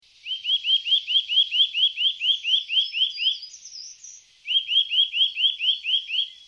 So klingt der Kleiber
der-kleiber-stimme.mp3